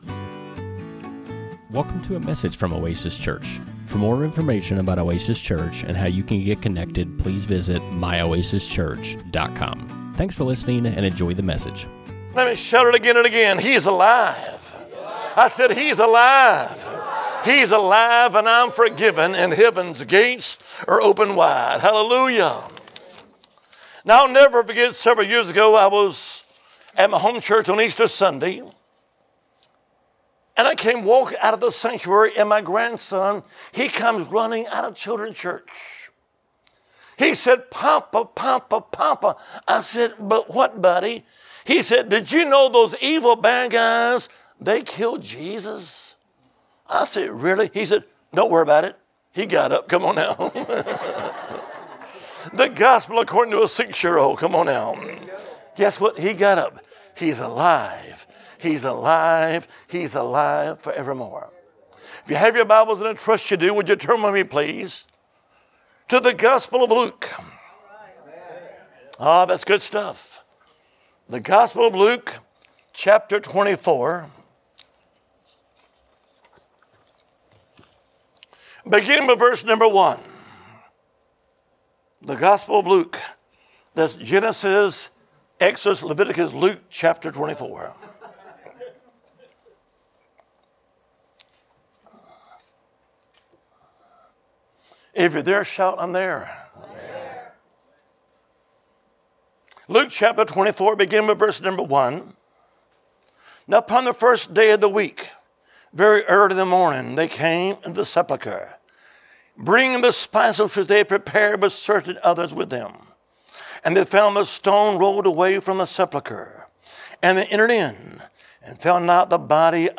Speaker Evangelist